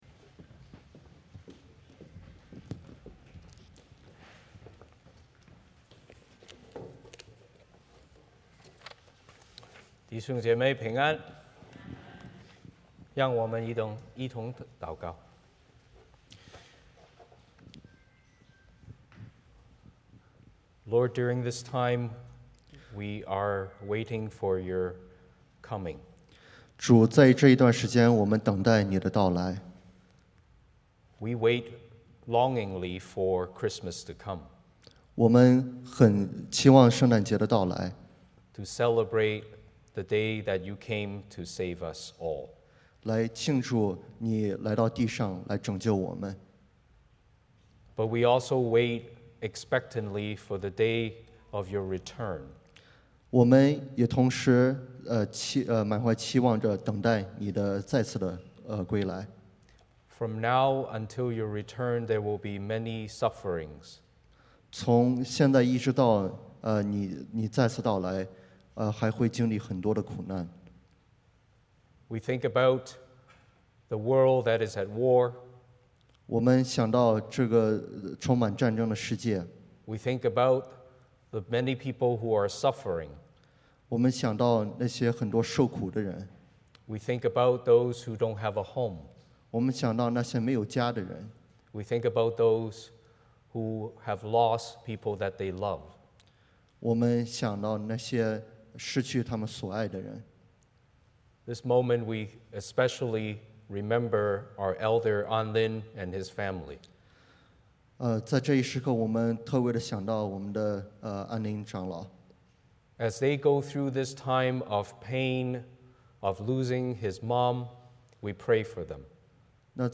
Service Type: 主日崇拜 欢迎大家加入我们的敬拜。